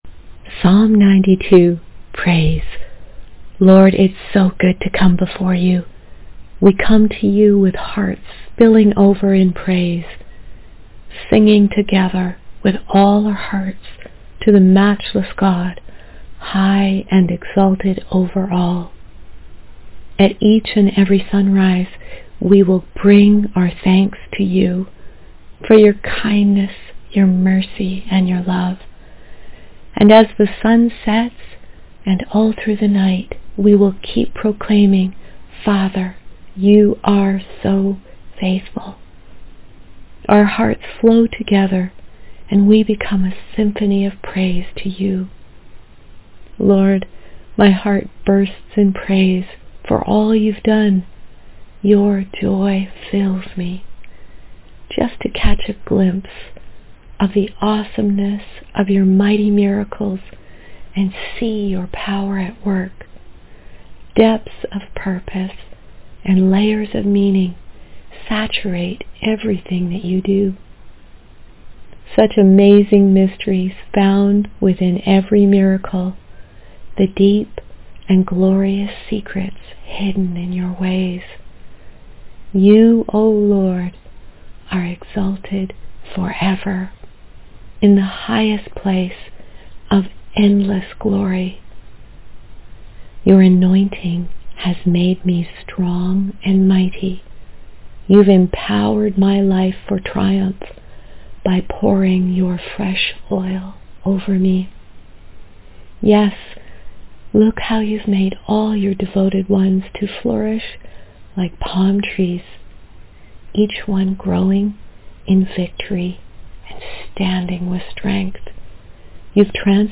Psalm 92 Audio Prayer and Verse
Psalm-92.mp3